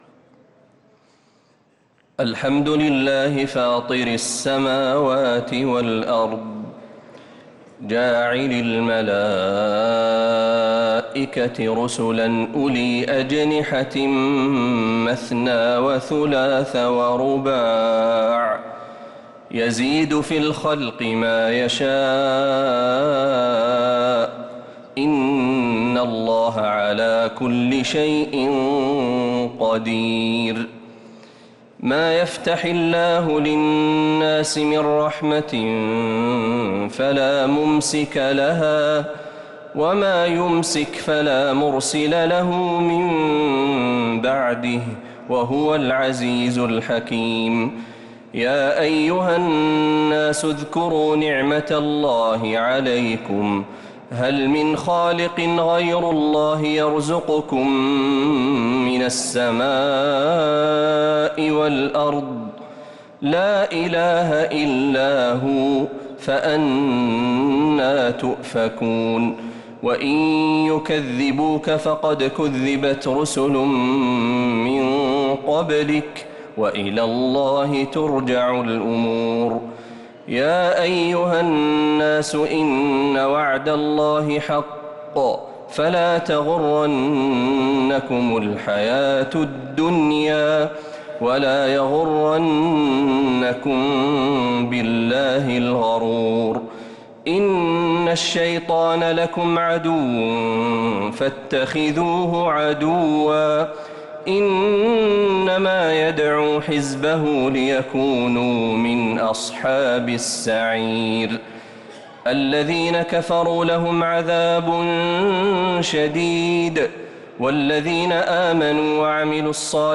سورة فاطر كاملة من تهجد الحرم النبوي